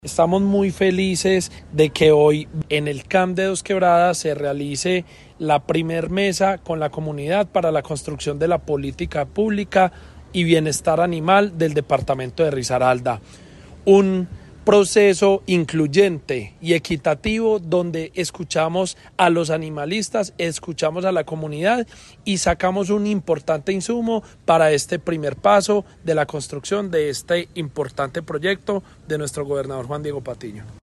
El evento inaugural, realizado en el Concejo Municipal de Dosquebradas, reunió a representantes de la comunidad, expertos en bienestar animal, gremios productivos y entidades del Estado, marcando el inicio de una serie de mesas participativas que se llevarán a cabo en todos los municipios del departamento.